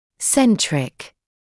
[‘sentrɪk][‘сэнтрик]центральный